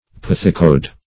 Search Result for " pithecoid" : The Collaborative International Dictionary of English v.0.48: Pithecoid \Pith"e*coid\, a. [Gr.
pithecoid.mp3